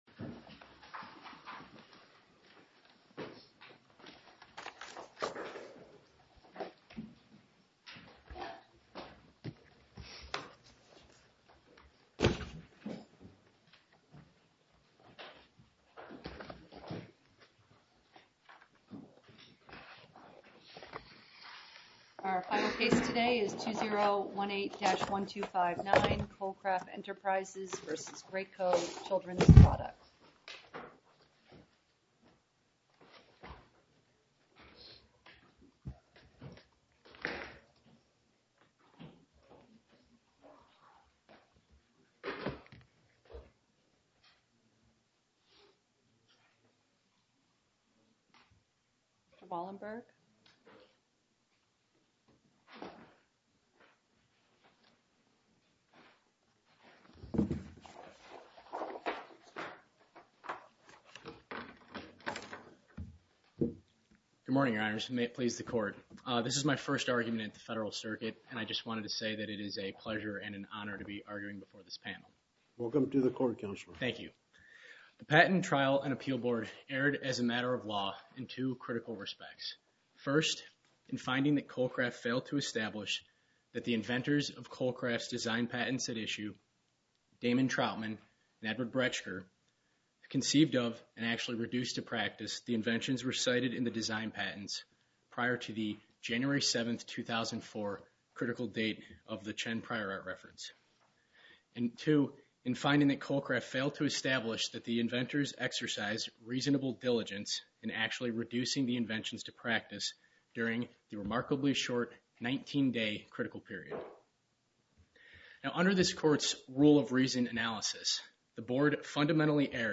Oral argument audio posted: Kolcraft Enterprises, Inc. v. Graco Children’s Products, Inc (mp3) Appeal Number: 2018-1259 To listen to more oral argument recordings, follow this link: Listen To Oral Arguments.